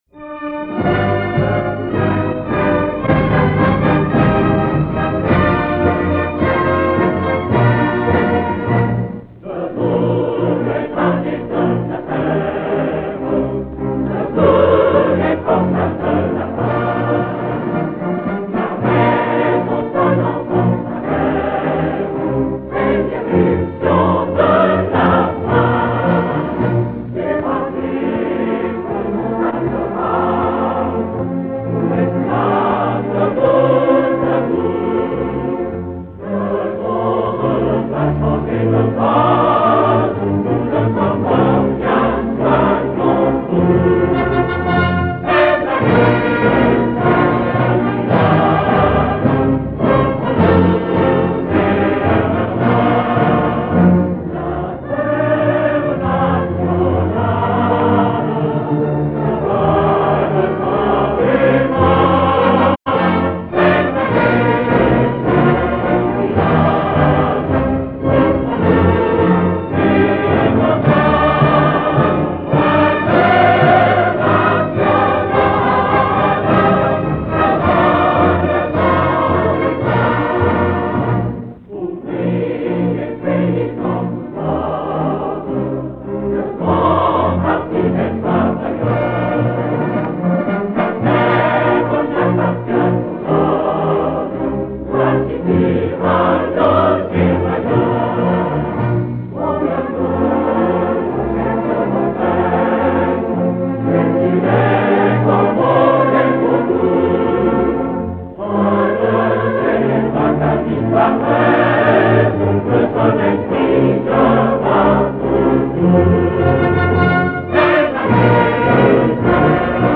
internationale-french chorus.mp3